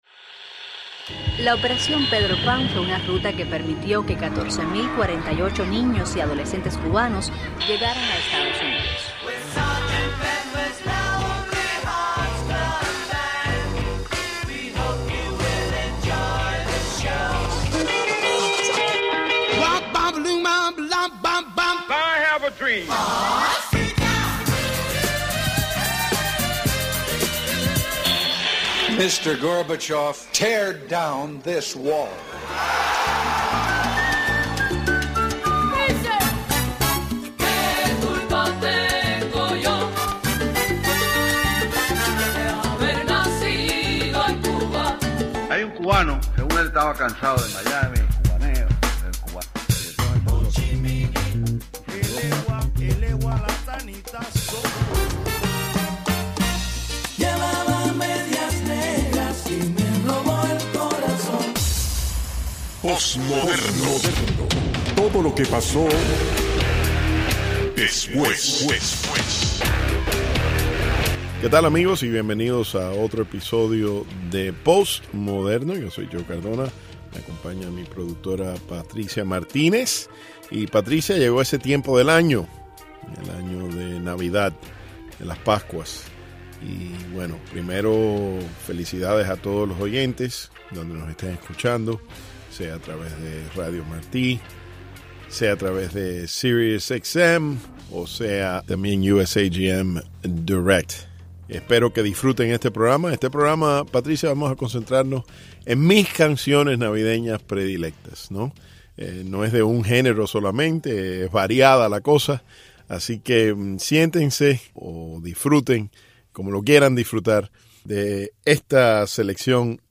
Selección de música navideña
Algunas de las canciones más populares que se escuchan en época navideña